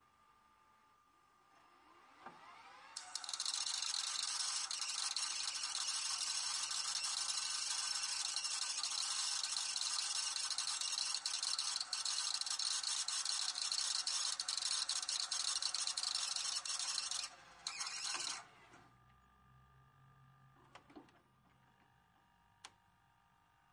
标签： abstract weird machine
声道立体声